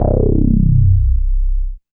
84 MOOG BASS.wav